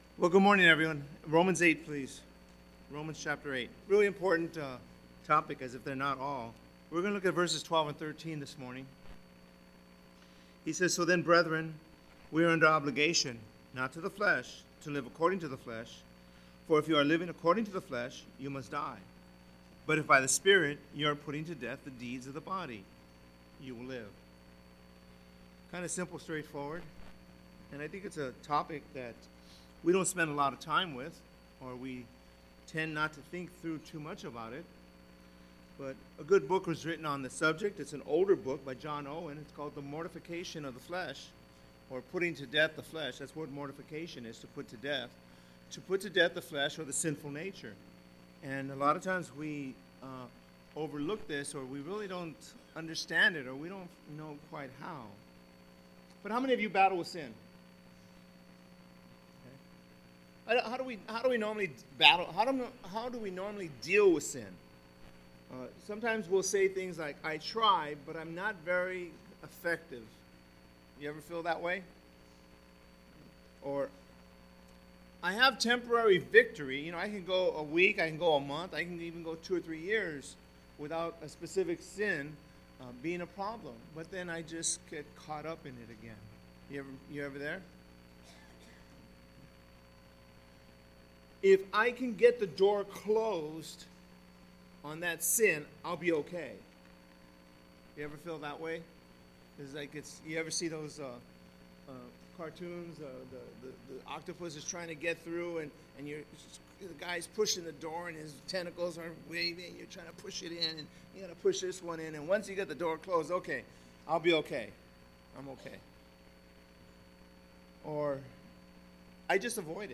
SERMONS - PICO RIVERA BIBLE CHURCH